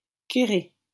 Querré (French pronunciation: [keʁe]